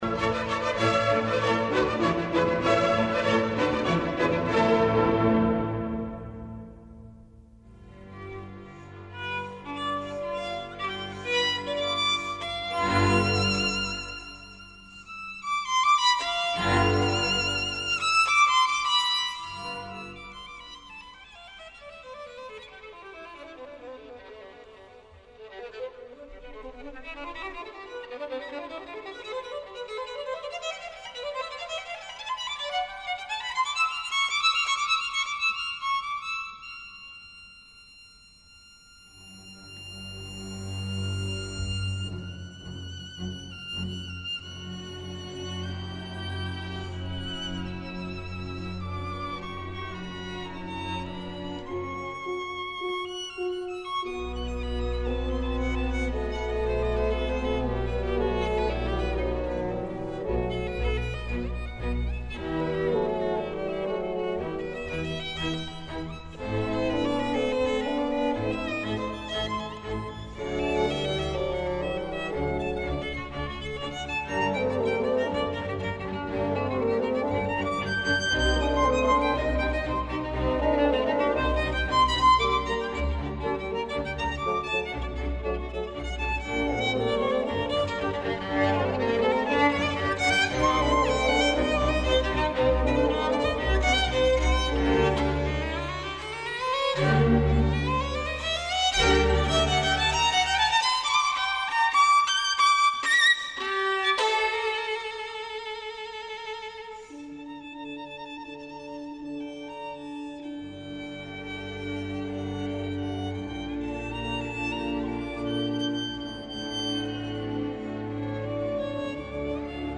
за виолину и оркестар